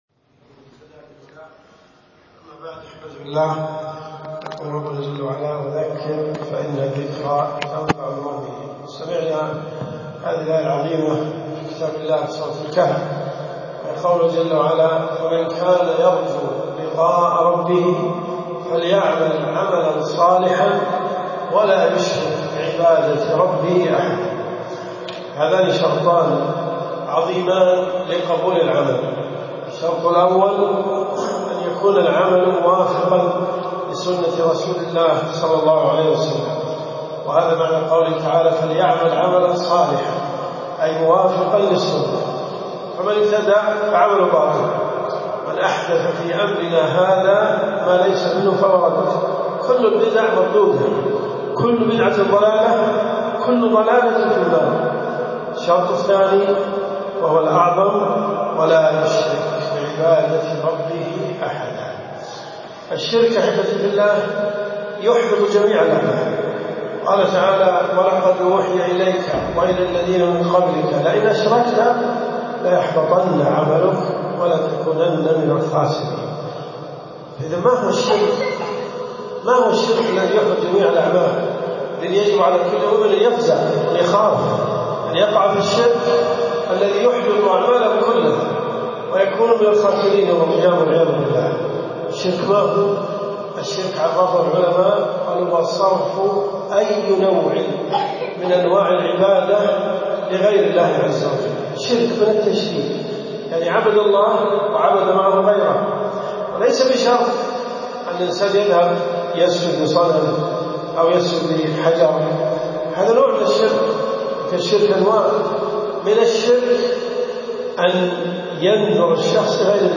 كلمات المساجد .
جامع العباس رضي الله عنه